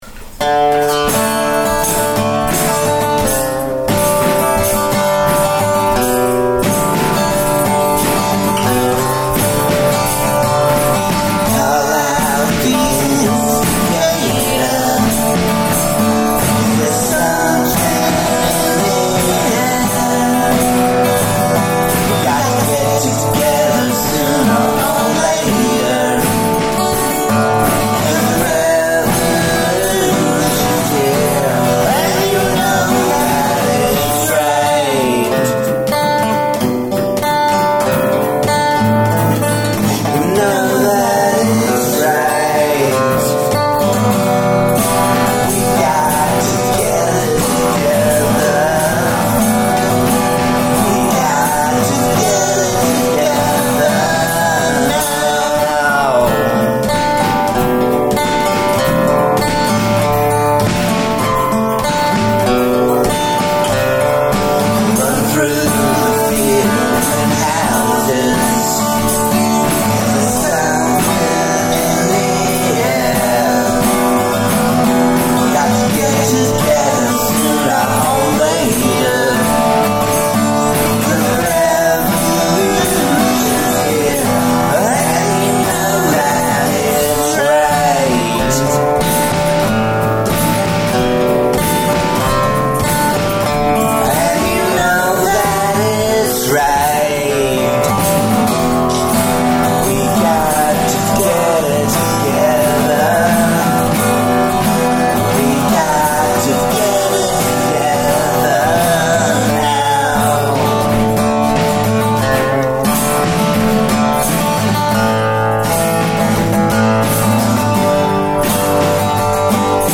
cover of a classic pop song